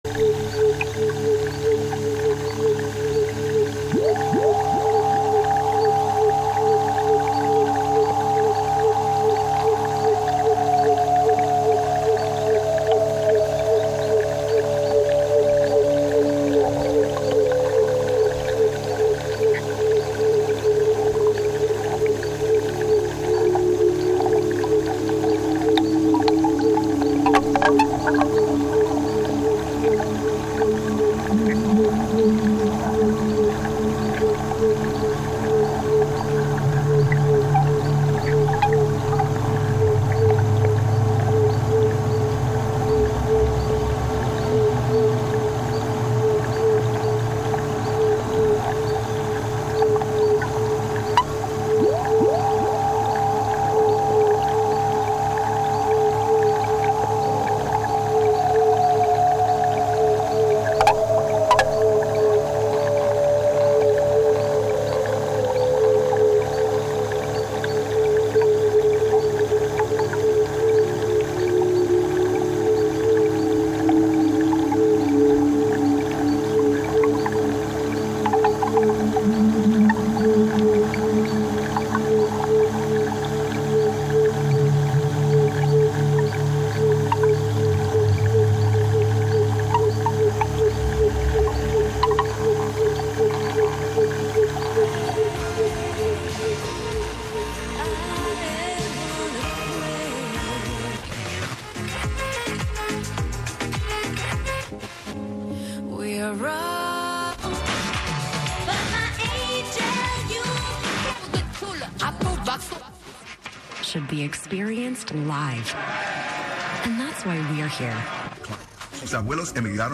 Live from Brooklyn, NY